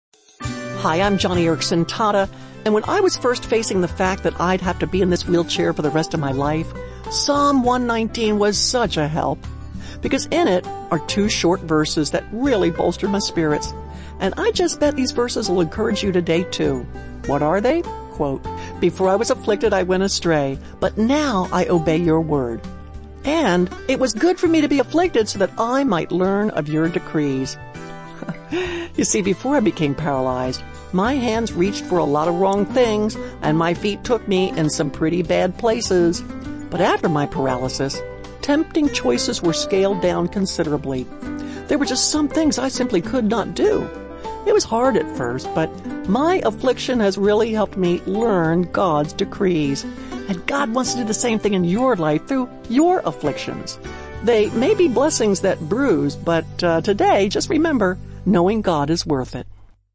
By |Published On: November 9, 2019|Categories: 1-Minute Radio Program|